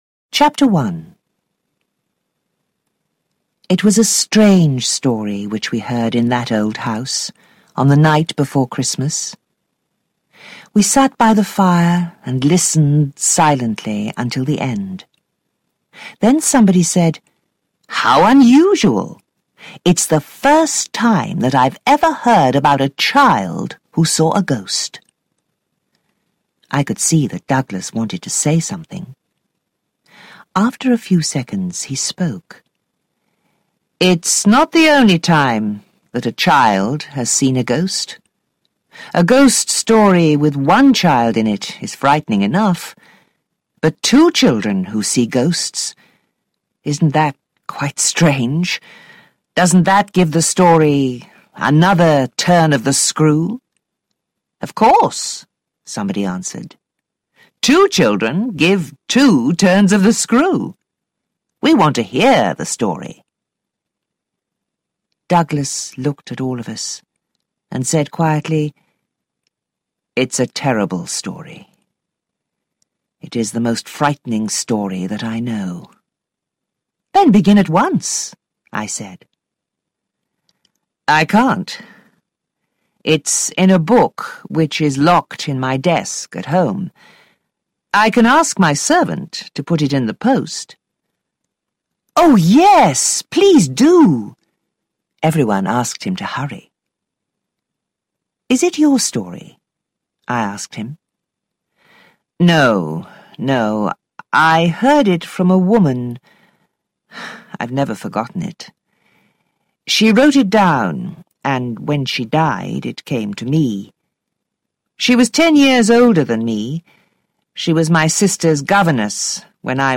Listen & Read online or download for free graded reader audiobook English7Levels.